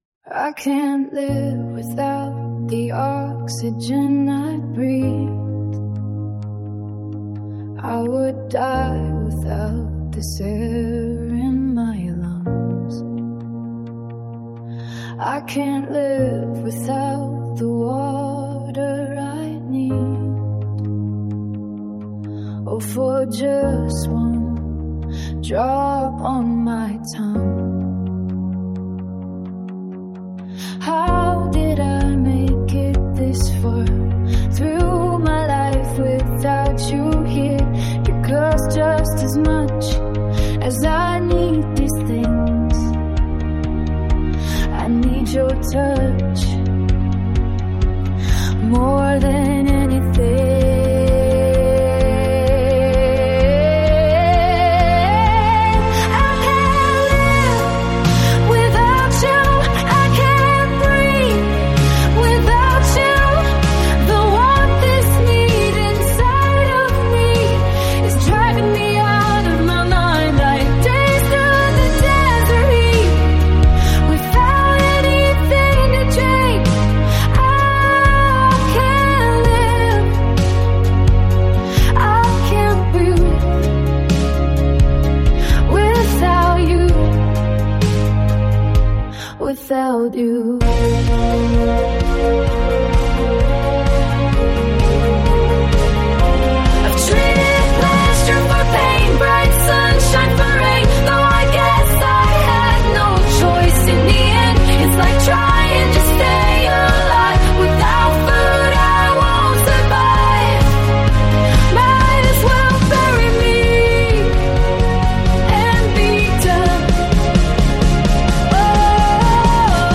neo pop song